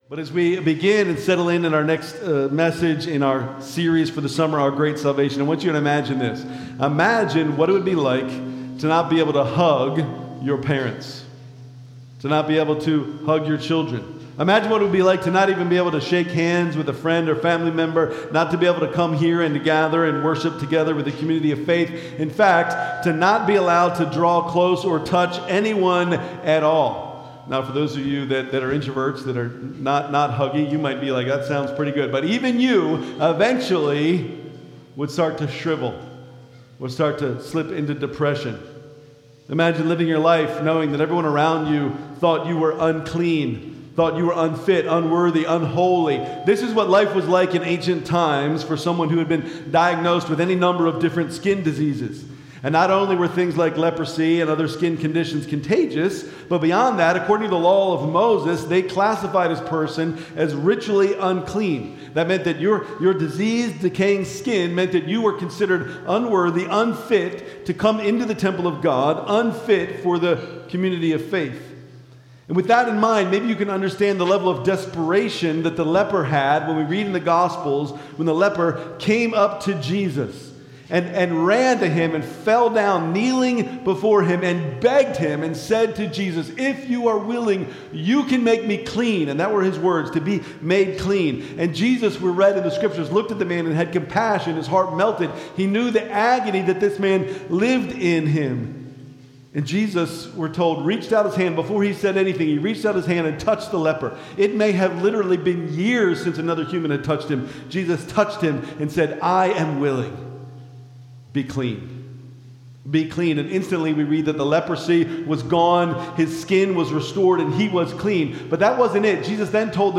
Aug 3, 2025 Worship Service Order of Service: